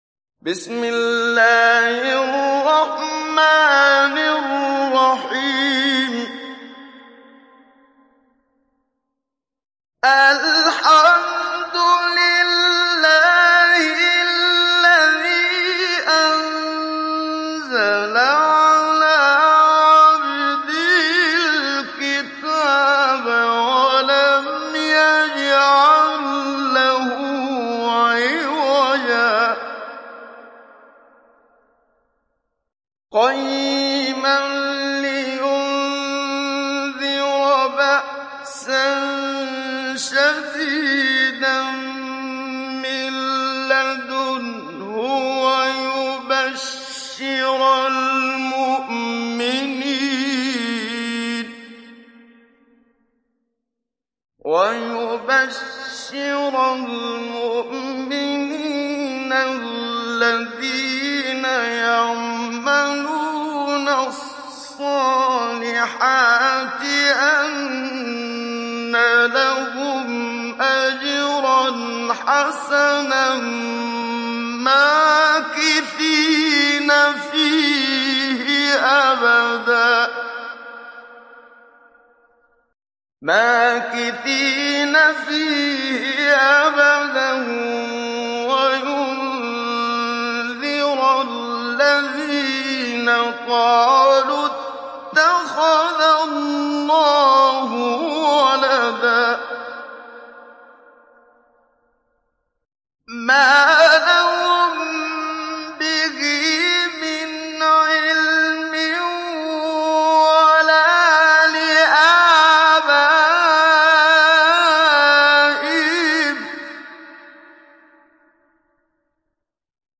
Kehf Suresi İndir mp3 Muhammad Siddiq Minshawi Mujawwad Riwayat Hafs an Asim, Kurani indirin ve mp3 tam doğrudan bağlantılar dinle